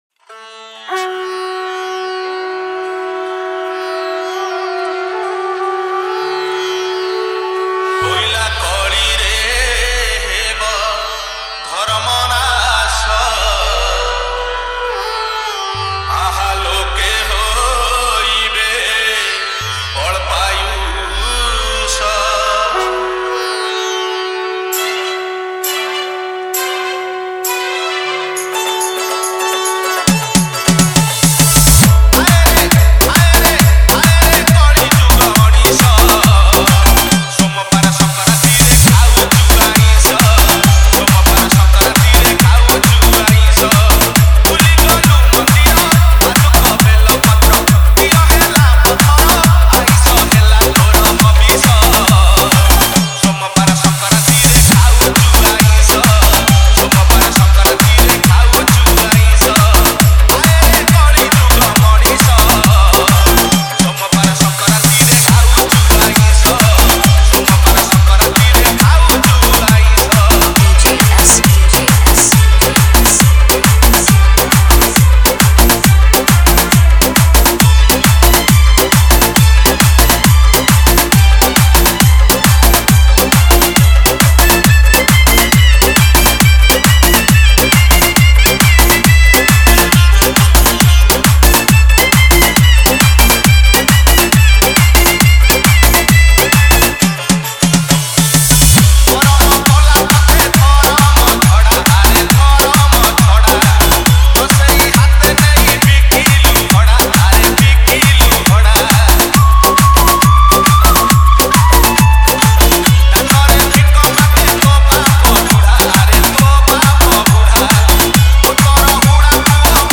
Shivratri Special DJ Remix Songs Songs Download